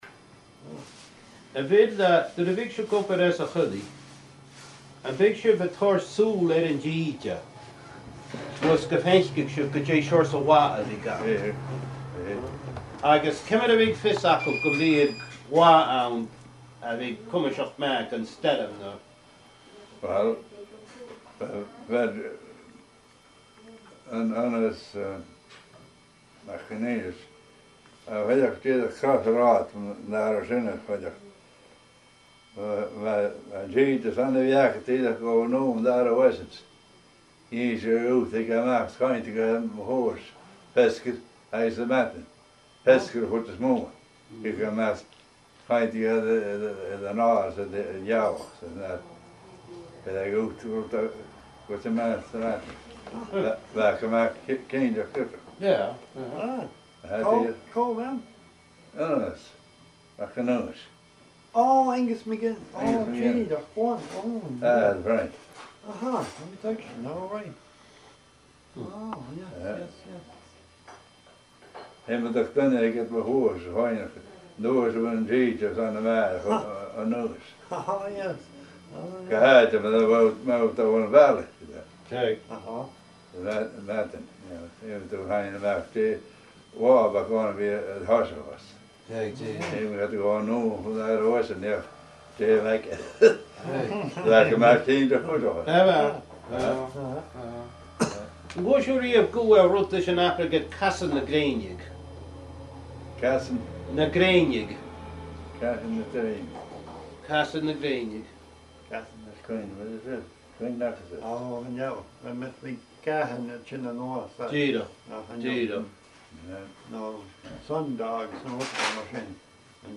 An Neach-agallaimh